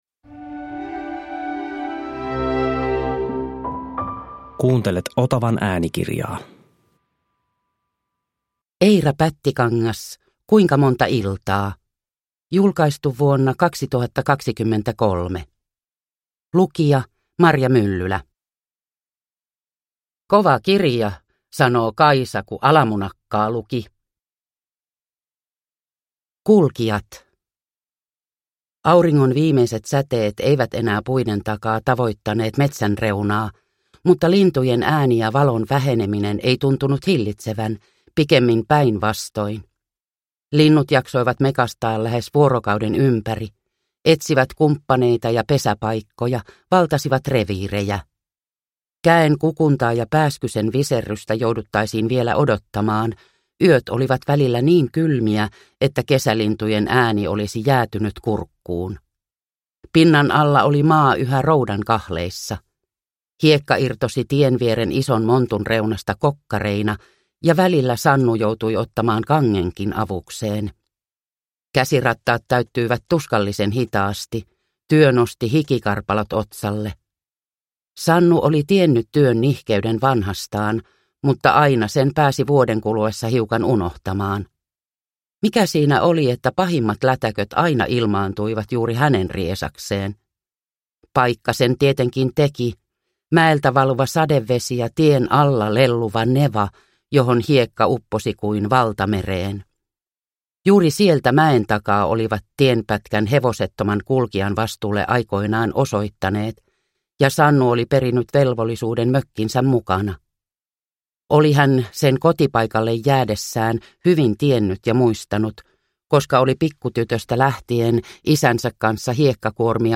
Kuinka monta iltaa – Ljudbok – Laddas ner